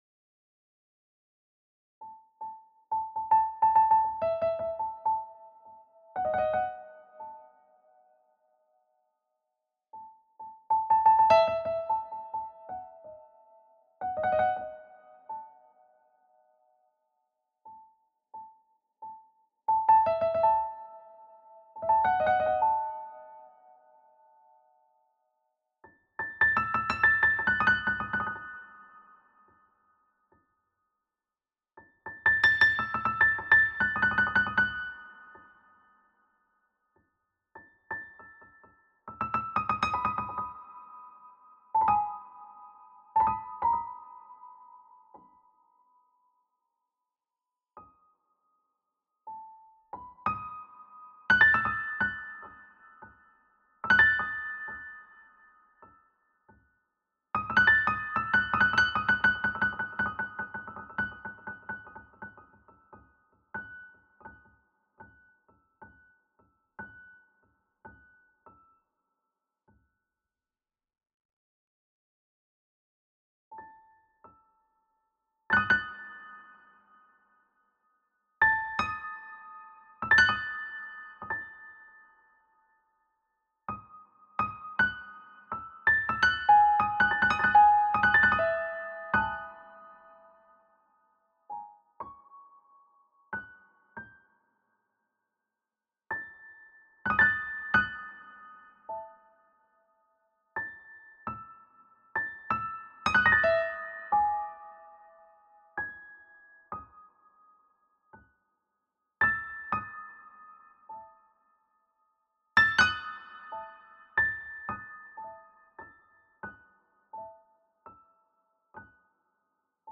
Musik, die alle 20 Minuten leise auftaucht: